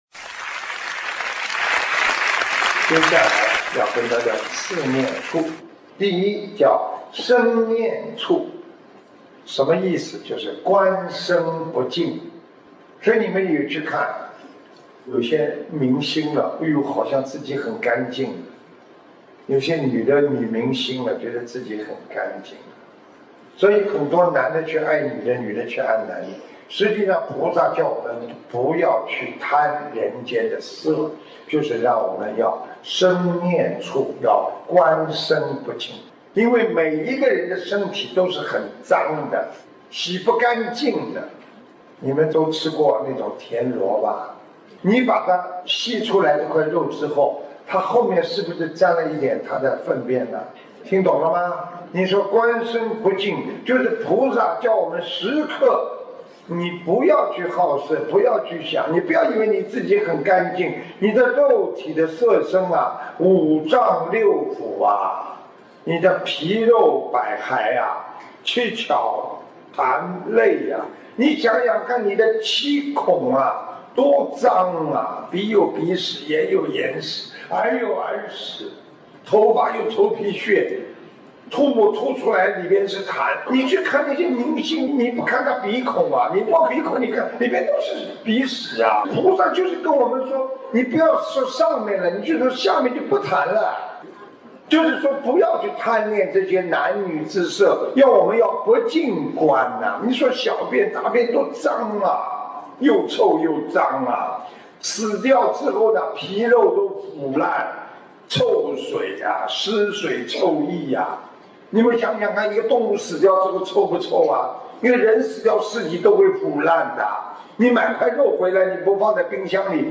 77【修四念处 以慧为体】-白話佛法广播讲座（视音文）